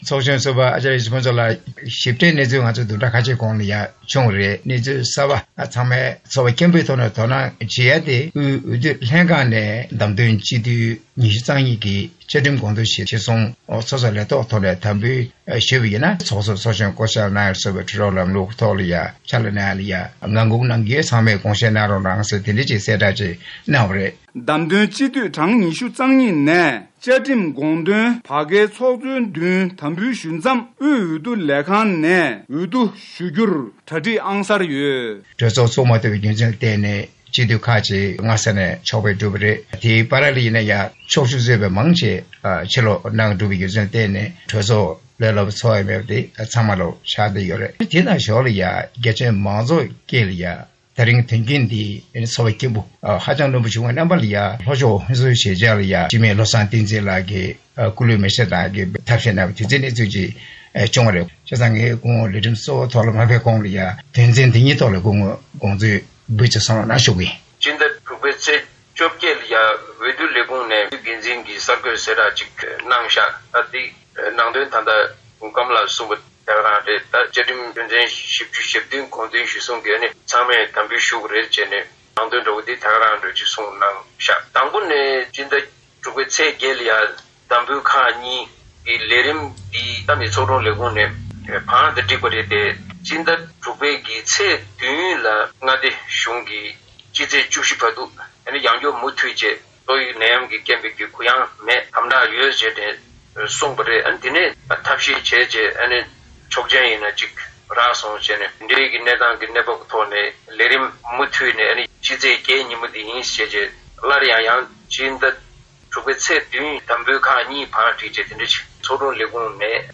བོད་མི་མང་སྤྱི་འཐུས་ཚོགས་གཙོ་དང་ཚོགས་གཞོན་བསྐོ་གཞག་གནང་མ་ཐུབ་པའི་གནས་སྟངས་དང་འབྱུང་འགྱུར་བཅའ་ཁྲིམས་དང་ཁྲིམས་སྒྲིག་ལ་བསྐྱར་བཅོས་ཇི་དགོས་སྐོར་བགྲོ་གླེང་།